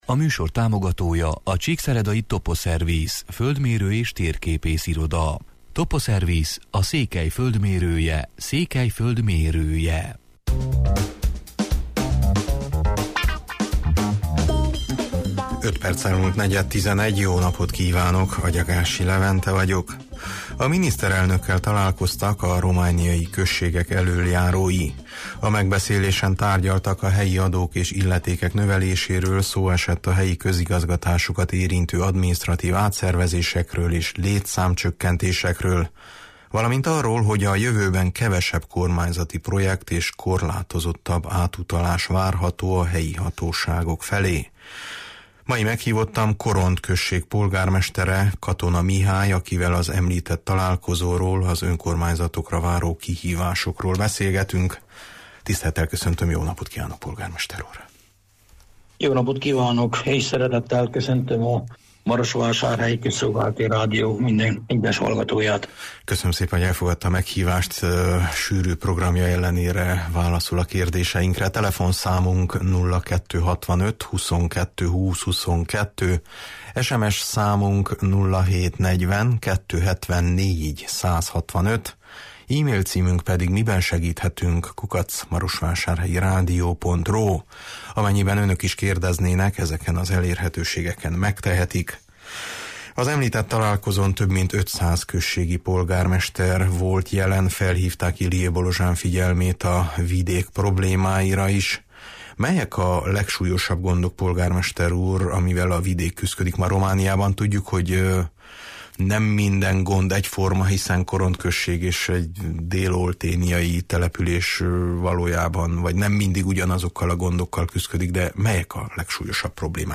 Meghívottam Korond polgármestere, Katona Mihály, akivel az említett találkozóról, az önkormányzatokra váró kihívásokról beszélgetünk: